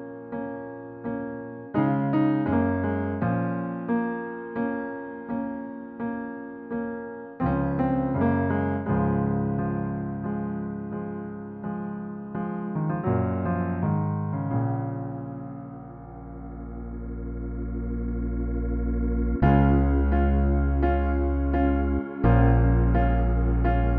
- no Backing Vocals R'n'B / Hip Hop 3:41 Buy £1.50